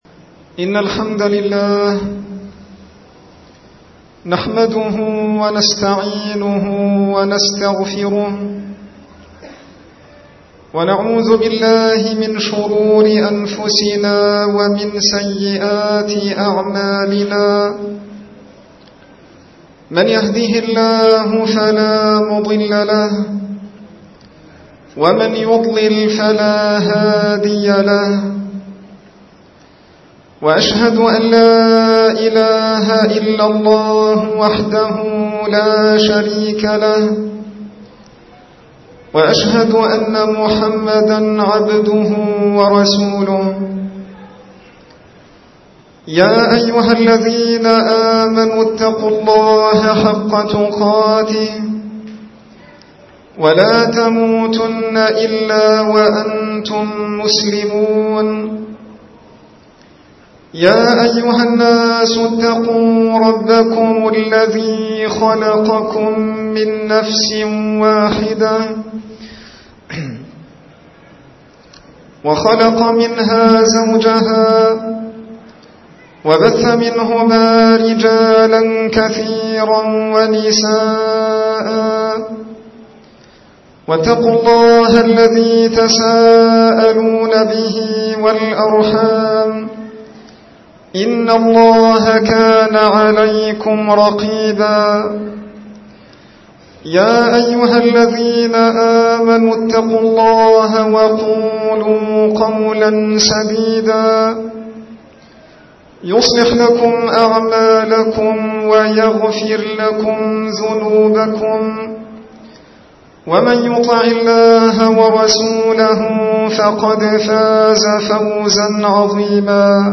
خطب عامة